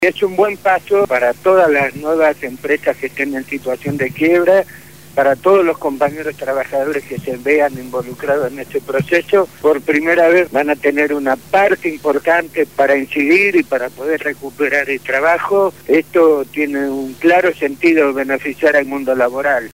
habló en el Programa Punto de Partida (Lunes a viernes de 7 a 9 de la mañana) de Radio Gráfica FM 89.3